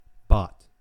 enPR: bŏt, IPA: /bɒt/, SAMPA: /bQt/
wymowa amerykańska
En-us-bot.ogg.mp3